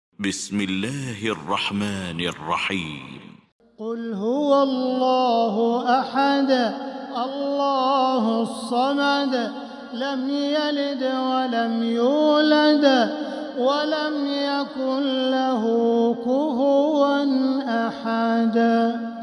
سورة الإخلاص Surat Al-Ikhlas > مصحف تراويح الحرم المكي عام 1446هـ > المصحف - تلاوات الحرمين